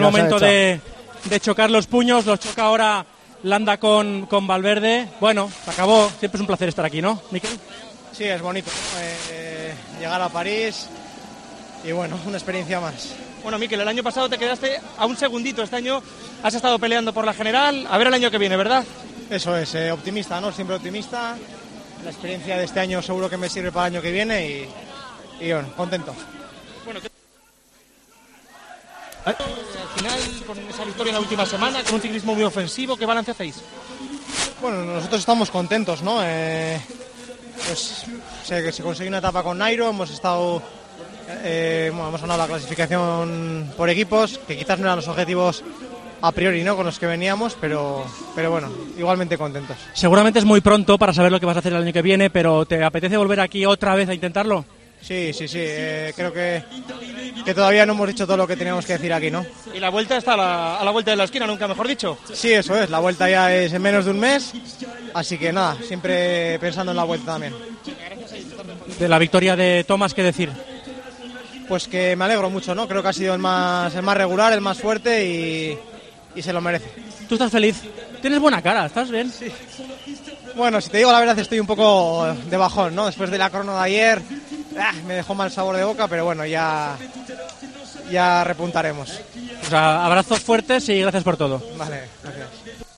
El ciclista español Mikel Landa habló después de la última etapa del Tour de Francia, analizó los objetivos del equipo y su descontento con su última contrarreloj.